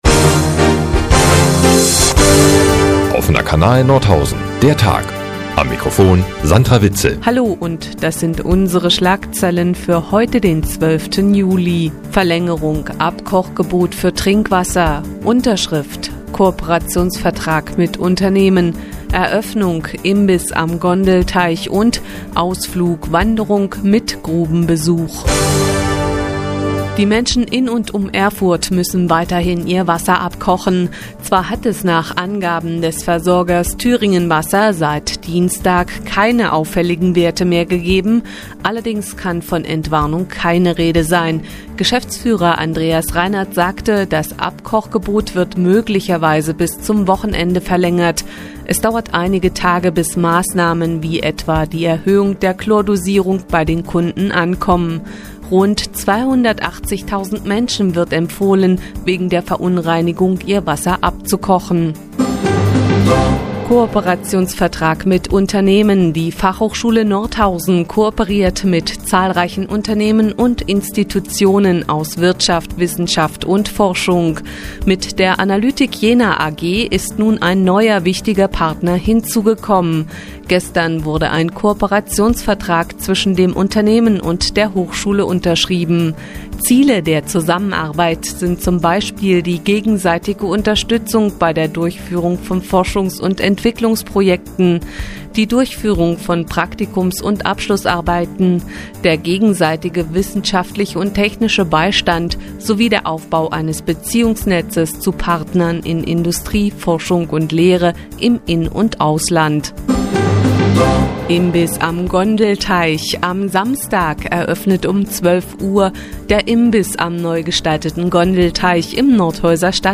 12.07.2012, 15:51 Uhr : Seit Jahren kooperieren die nnz und der Offene Kanal Nordhausen. Die tägliche Nachrichtensendung des OKN ist jetzt hier zu hören.